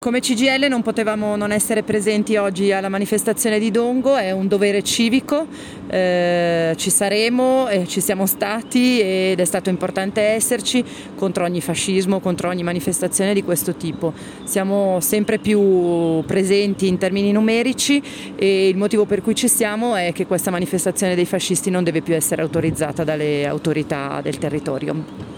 Dichiarazioni alla fine della manifestazione